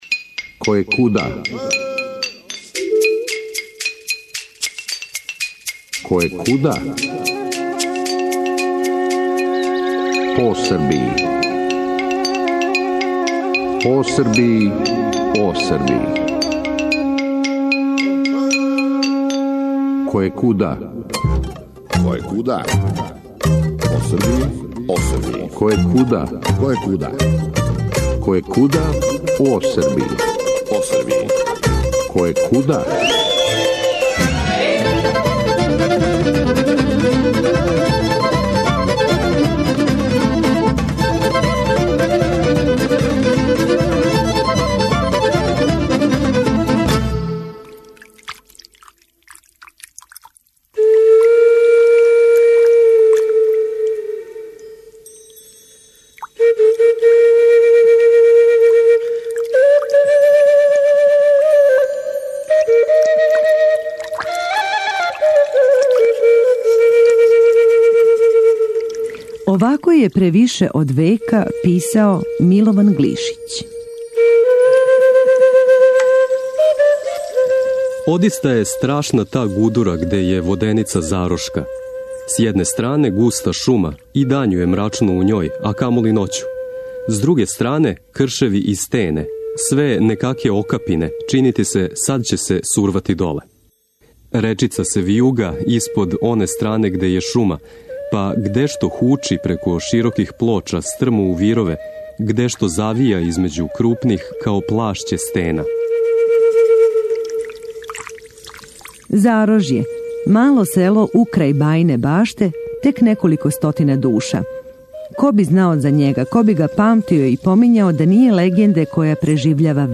Мештани препричавају причу онако како су запамтили од својих старих који су је њима казивали и актере догађаја, за разлику од чувеног српског приповедача помињу и именом и презименом, и старином и пореклом, тако да слушалац стиче утисак да се време напросто зауставило и да се јунаштво младог Страхиње Ђиласа, који је жив преноћио и зарошкој воденици и с два куршума упуцао вампира Саву, стварно збило и то недавно.